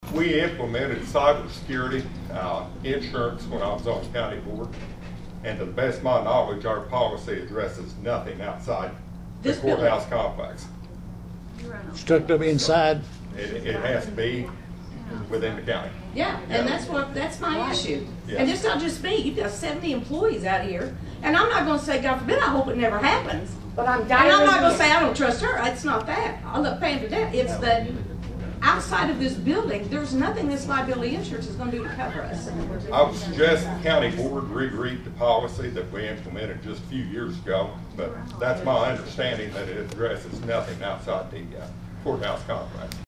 Over the next hour, only one individual abided by that request and it was mostly a group conversation that resembled Great Britain’s parliamentary procedures with interruptions and occasional raised voices.
Former County Board Chair David Dosher weighed in.